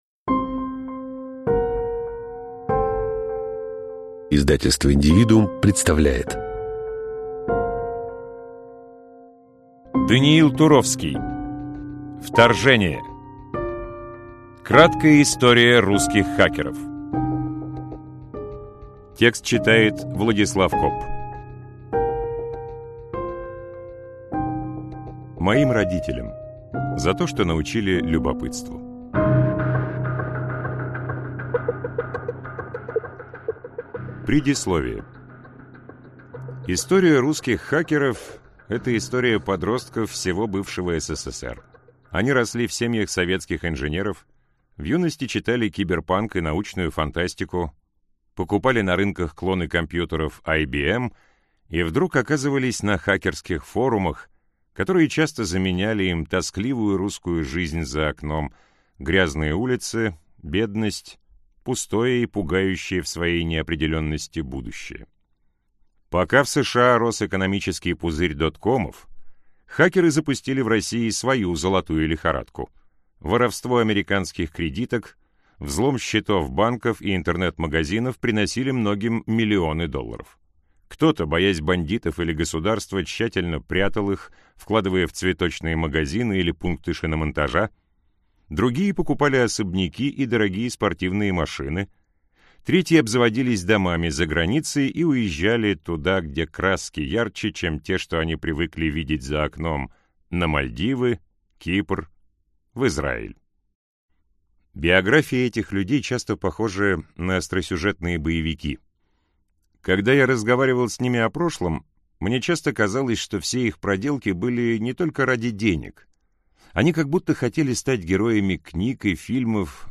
Аудиокнига Вторжение. Краткая история русских хакеров | Библиотека аудиокниг